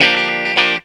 GTR 85 GM.wav